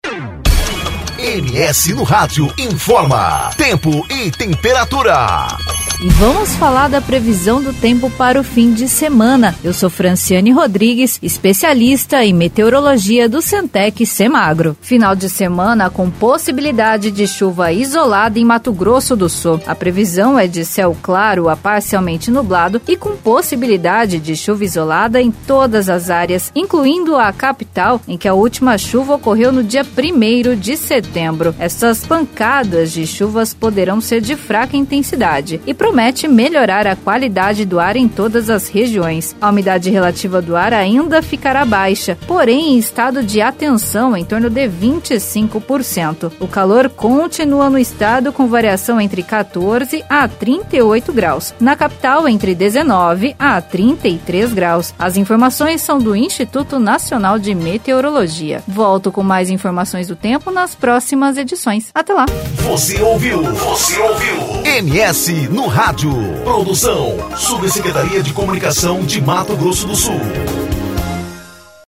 Confira a previsão do tempo do Cemtec/MS para o final de semana